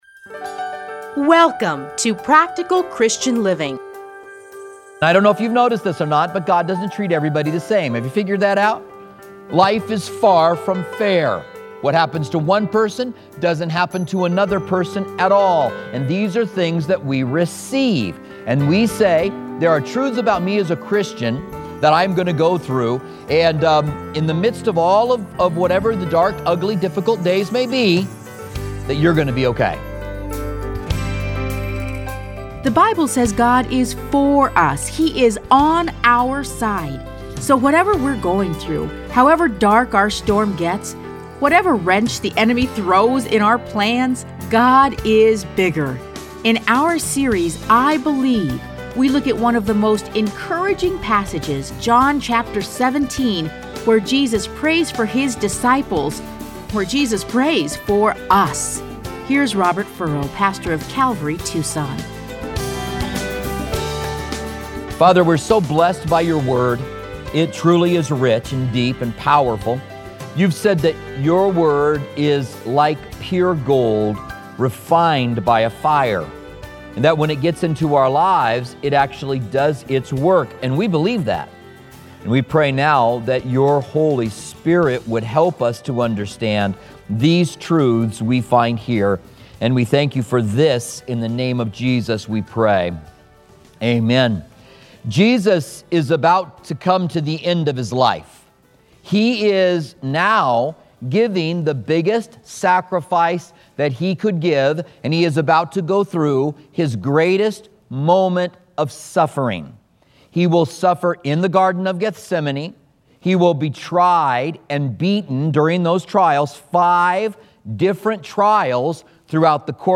Listen to a teaching from John John 17:6-26.